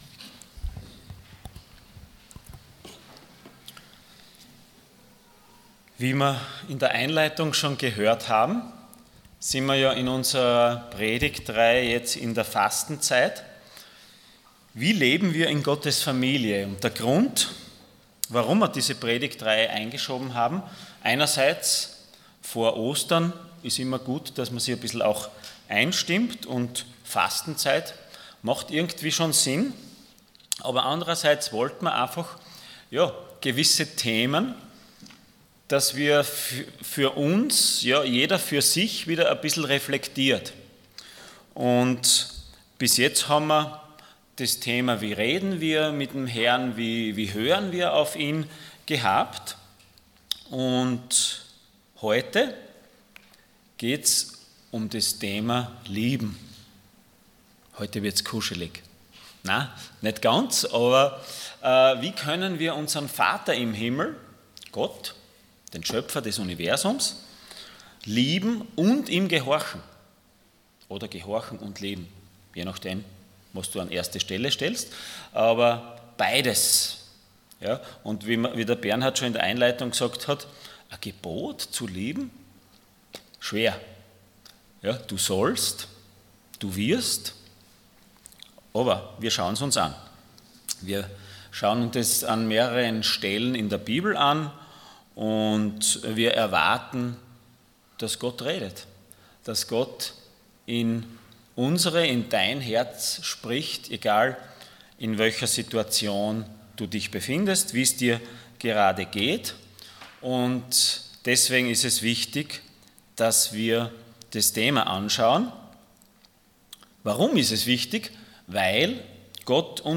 Passage: Matthäus 22,37 Dienstart: Sonntag Morgen Wie können wir unseren Gott lieben und gehorchen?